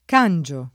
vai all'elenco alfabetico delle voci ingrandisci il carattere 100% rimpicciolisci il carattere stampa invia tramite posta elettronica codividi su Facebook cangiare v.; cangio [ k # n J o ], -gi — fut. cangerò [ kan J er 0+ ]